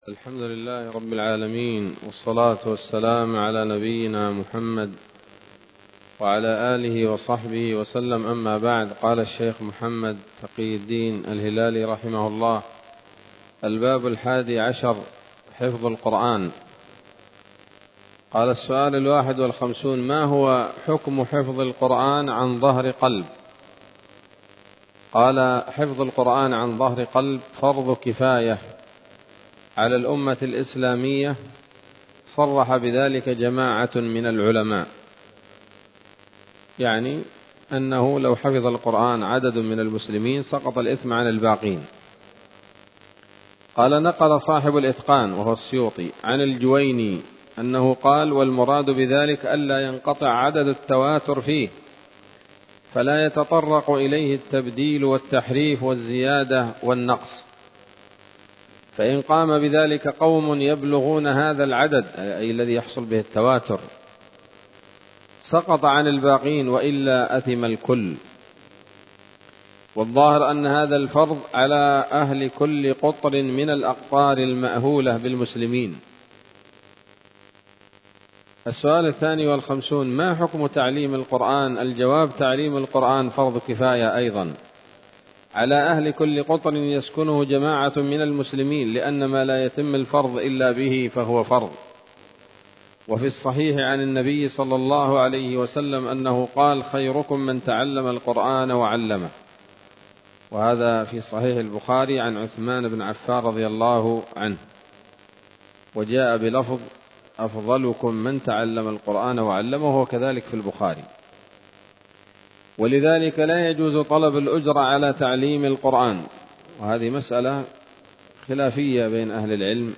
الدرس السابع عشر من كتاب نبذة من علوم القرآن لـ محمد تقي الدين الهلالي رحمه الله